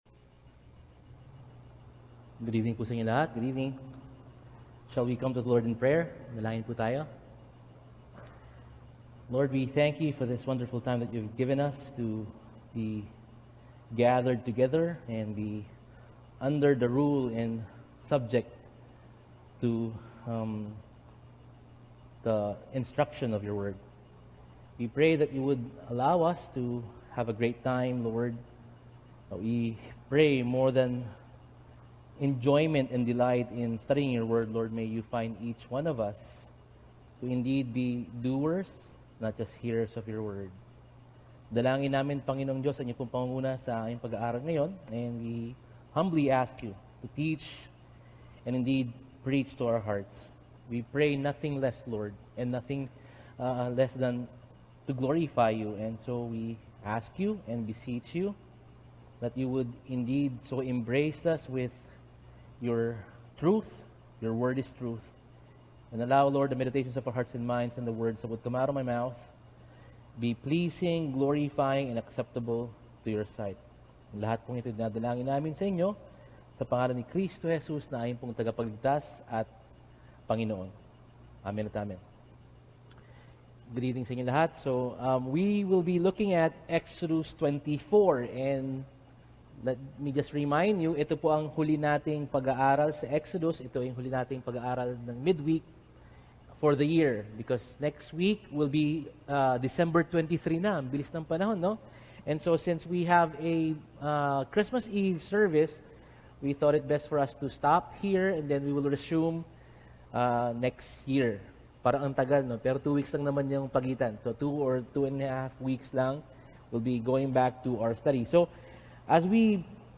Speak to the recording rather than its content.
Service: Midweek